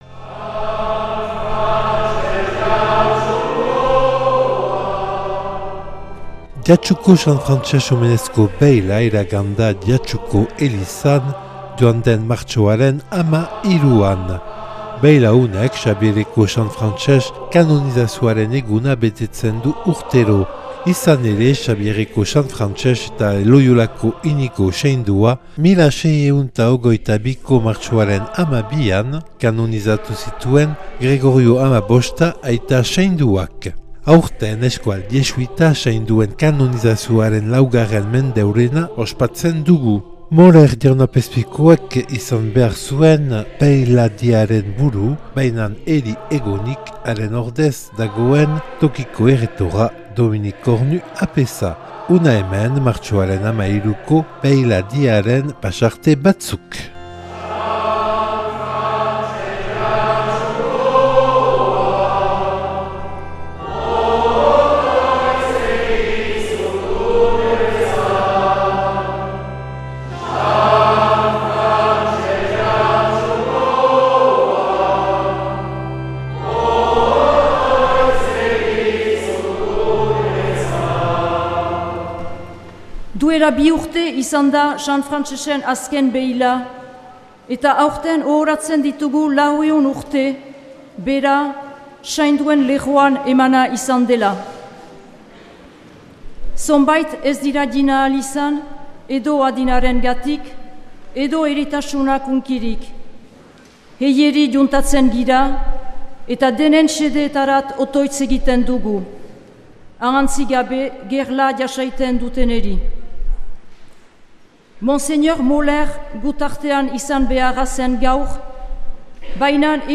Jatsuko beila 2022. martxoaren 13an